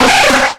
Cri de Nidoran♀ dans Pokémon X et Y.